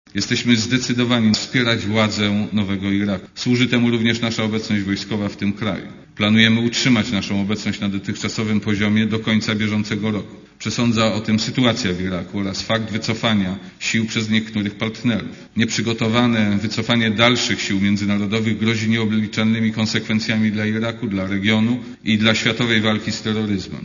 - mówił w sejmie szef MON Jerzy Szmajdziński.
Mówi minister Szmajdziński